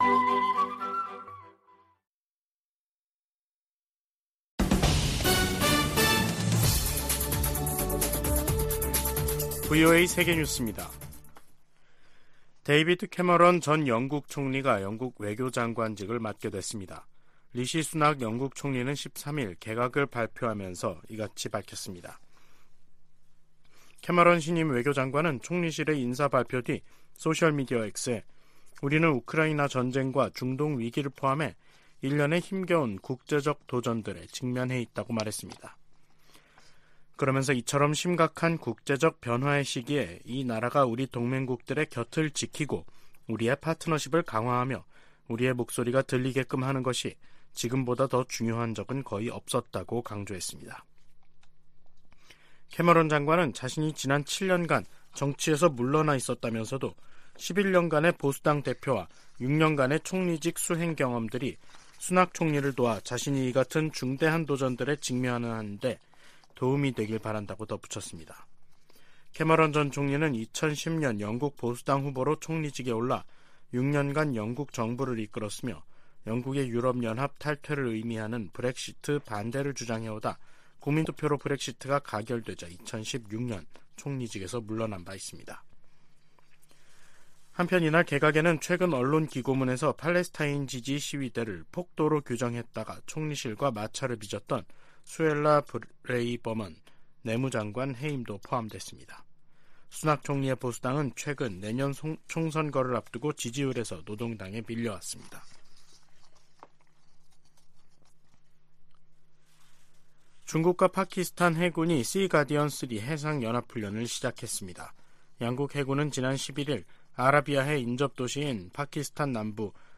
VOA 한국어 간판 뉴스 프로그램 '뉴스 투데이', 2023년 11월 13일 3부 방송입니다. 미국과 한국은 북한의 핵 위협에 대응해 맞춤형 억제전략(TDS)을 10년만에 개정하고, 미군 조기경보위성 정보 공유를 강화하기로 했습니다. 두 나라는 또 사이버안보 분야의 협력 강화를 위한 업무협약을 맺었습니다. 미국은 오는 15일의 미중 정상회담에서 북러 무기거래와 북한의 도발에 대한 우려, 한반도 비핵화 결의 등을 강조할 것이라고 밝혔습니다.